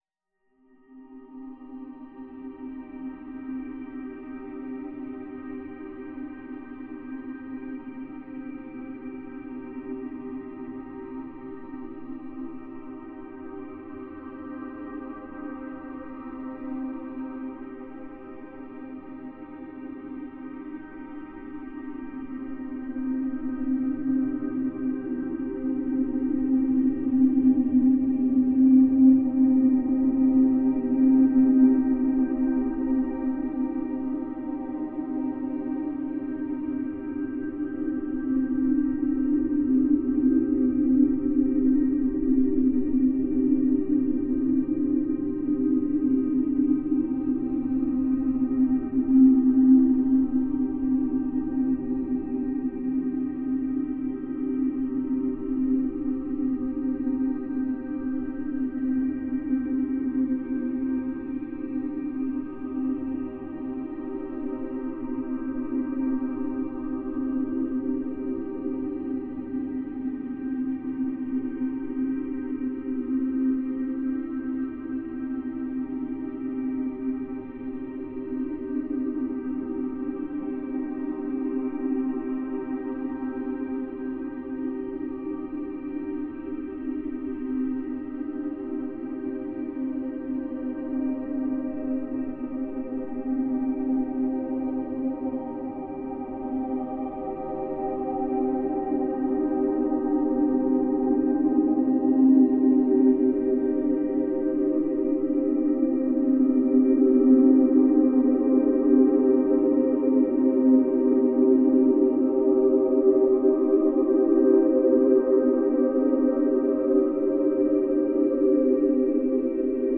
A remix of Avenue LeGrand, Brussels